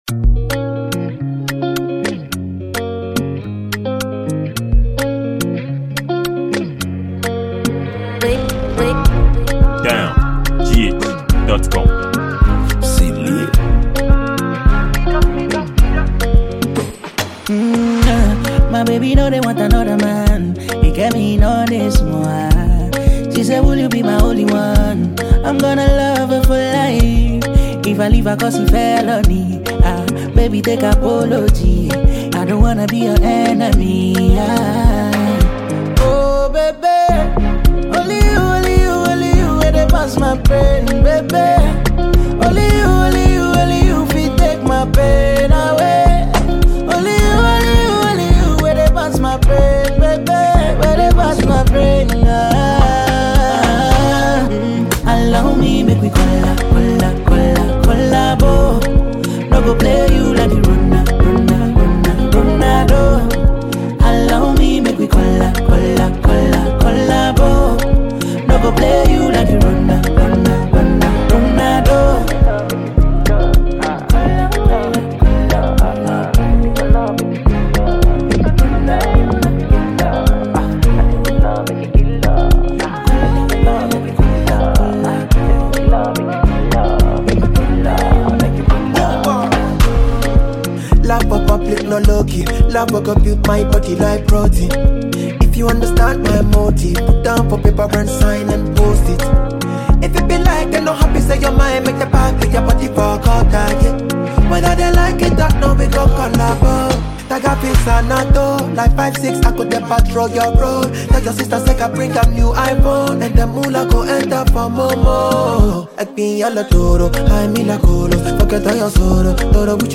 Ghanaian musician and singer